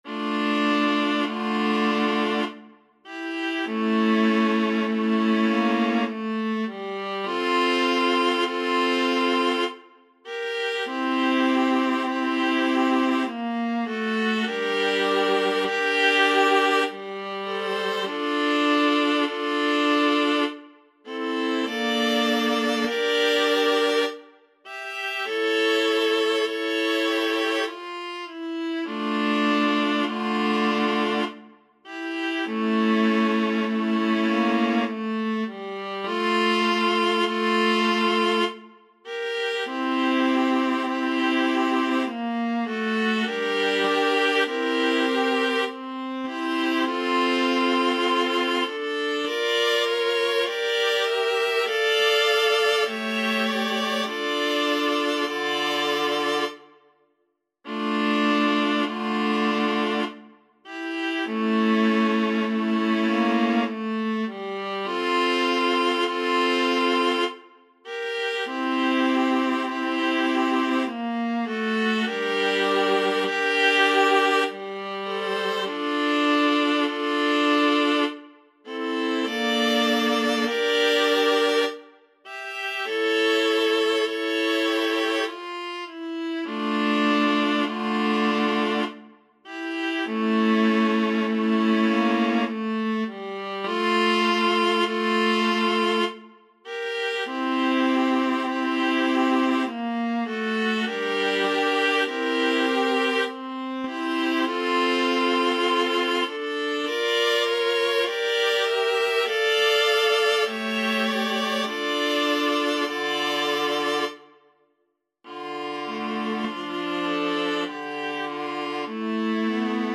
Viola 1Viola 2Viola 3Viola 4
Grave = 50
3/2 (View more 3/2 Music)
Classical (View more Classical Viola Quartet Music)